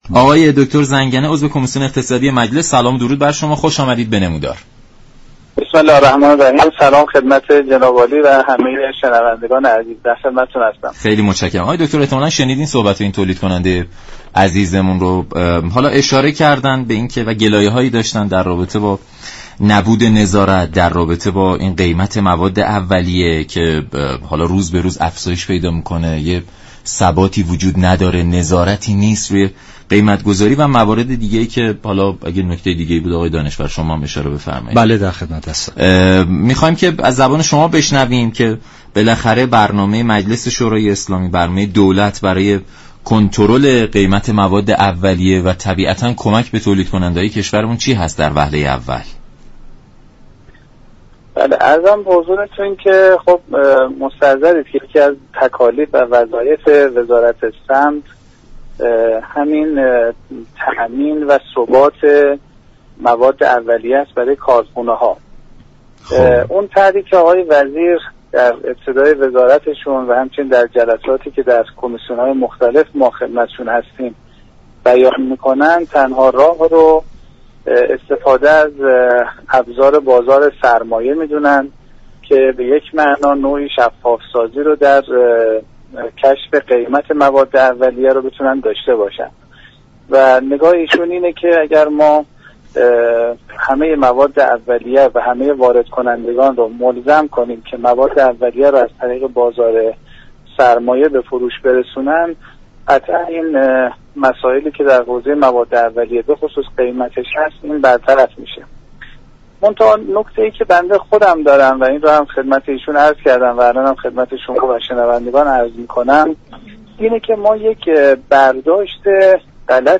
عضو كمیسیون اقتصادی مجلس گفت: در بازار سرمایه اگر زیرساخت ها فراهم نشود و ابزارهای لازم نیز وجود نداشته باشد، بازار سرمایه خود زمینه ساز رانت در كشور خواهد شد.